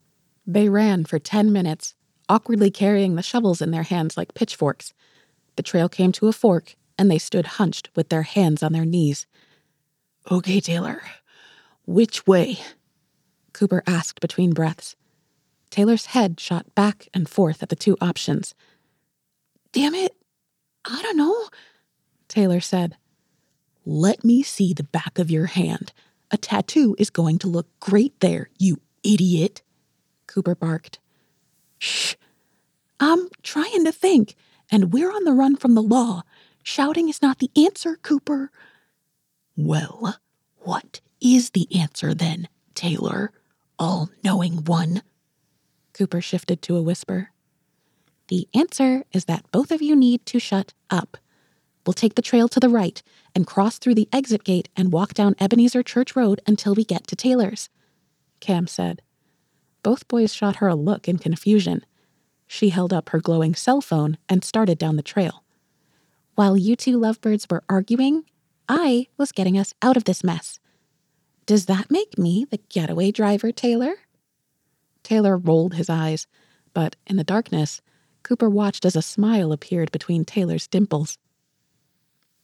Young Adult Adventure 3rd Person: Trying to escape capture (2M, 1F)
young-adult-adventure-sample.wav